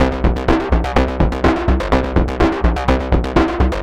tx_synth_125_pounder_CAbG1.wav